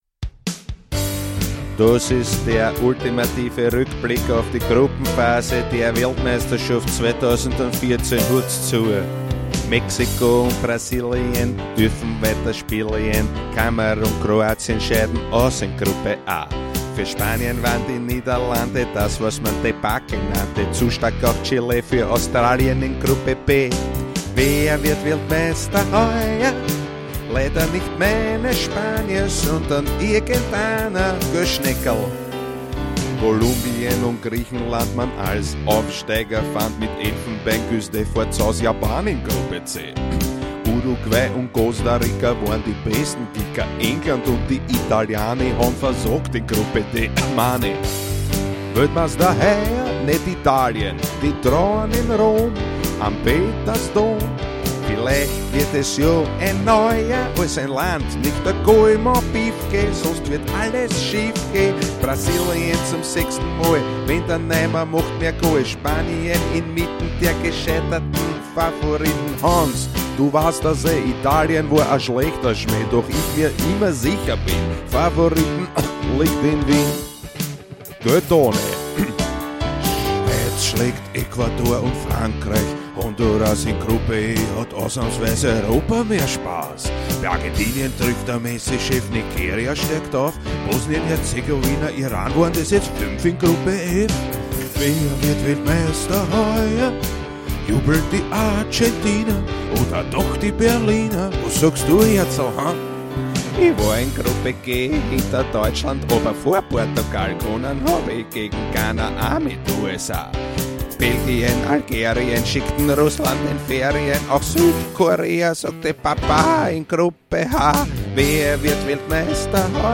Genre: Comedy.